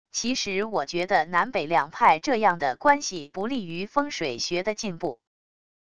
其实我觉得南北两派这样的关系不利于风水学的进步wav音频